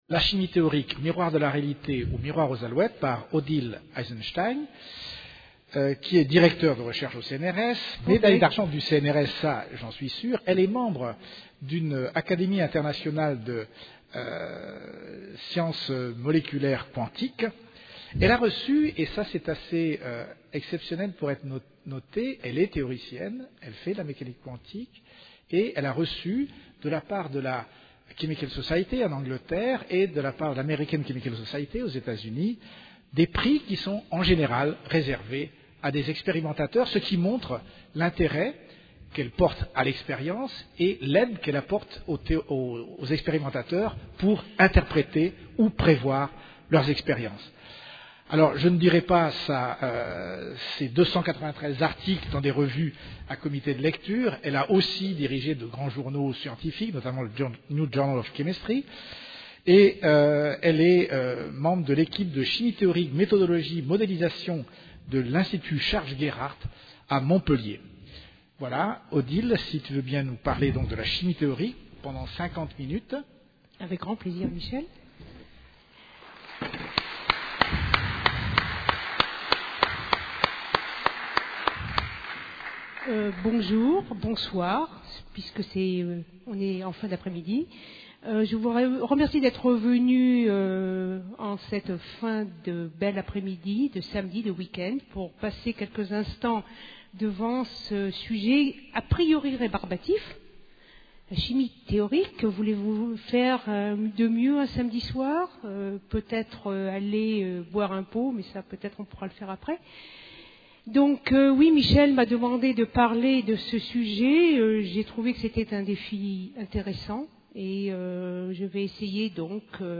Une conférence UTLS du cycle : « La Chimie partout » du 21 au 29 mai 2011 à 18h30